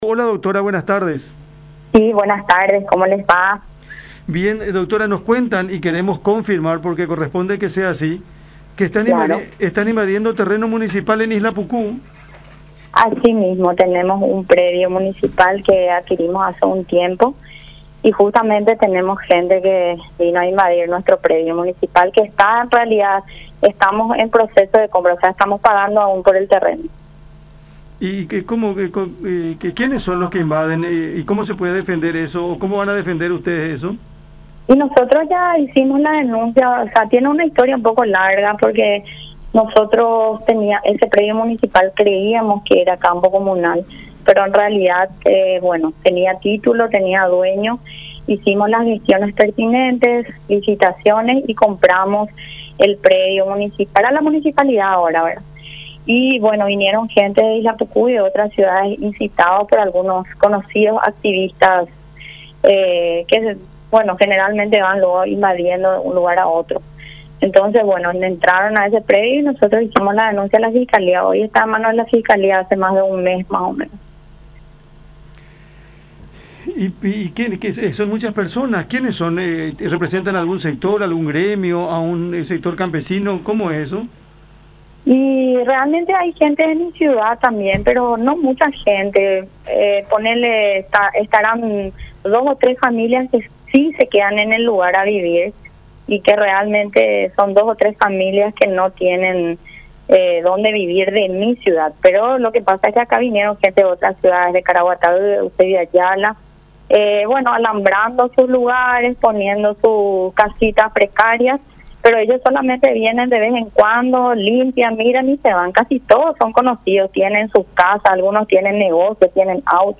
Dra. Sonia Fleitas (PLRA), Intendente Municipal Isla Pucú
“Ese predio municipal creíamos que era campo comunal pero en realidad, tenía titulo, tenía dueño, hicimos la gestiones pertinentes y compramos el predio para la municipalidad ahora, y buenos vinieron gente de Isla Pucú y otras ciudades, incitados por algunos activistas que generalmente van luego invadiendo de un lugar a otro”, sostuvo Fleitas en diálogo con La Unión R800 AM.